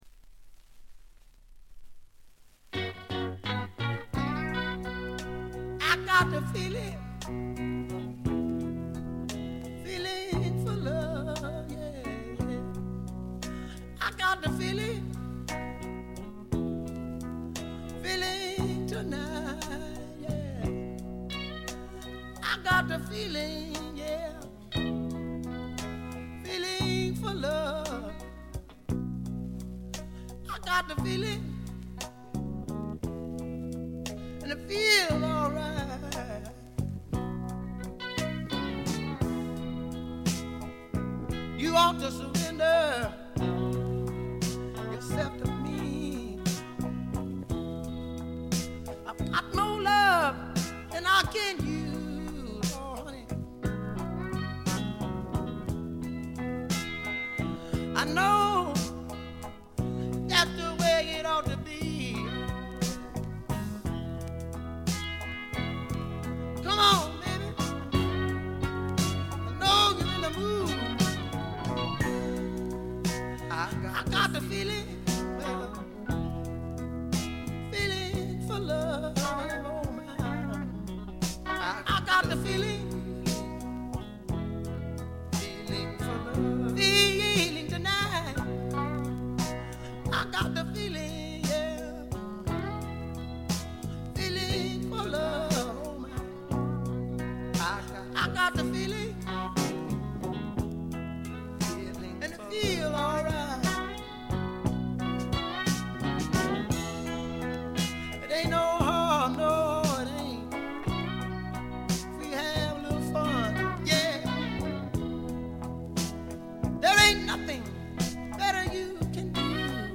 部分試聴ですが、わずかなノイズ感のみ。
予備知識がなければ100人が100人とも黒人シンガーと間違えてしまうだろうヴォーカルが、まず凄い！
試聴曲は現品からの取り込み音源です。